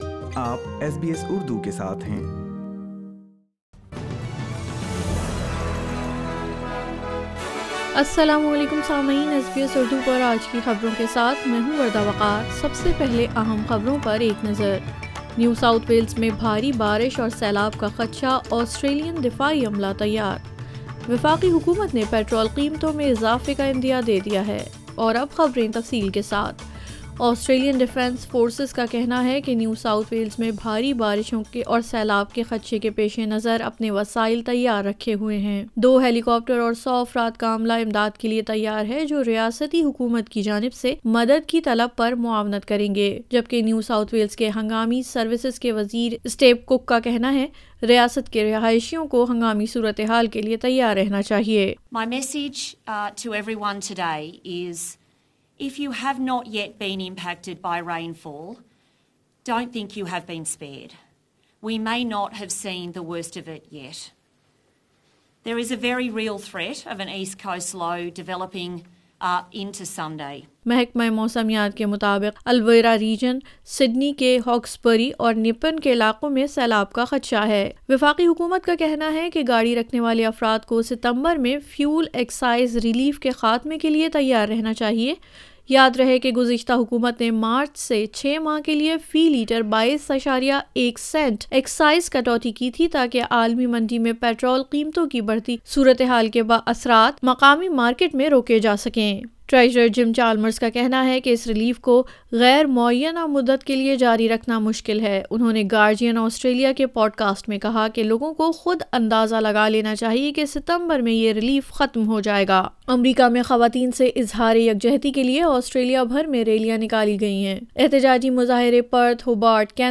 SBS Urdu News 02 July 2022